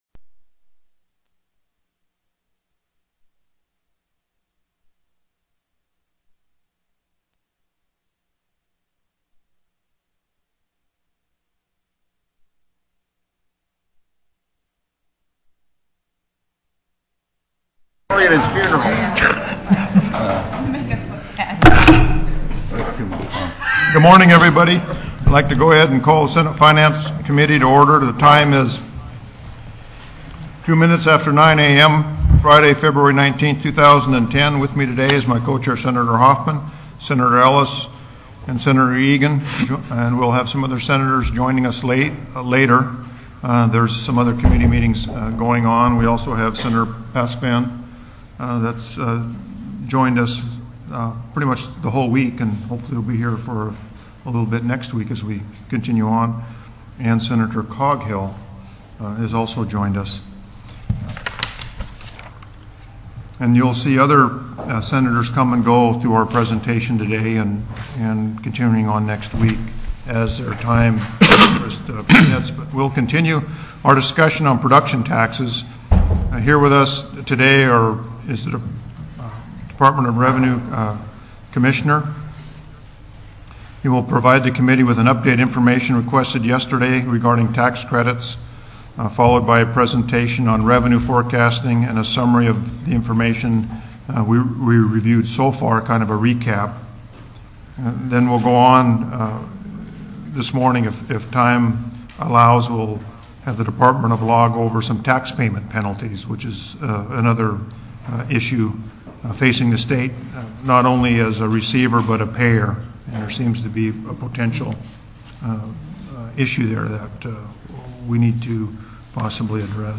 Production Tax Review: TELECONFERENCED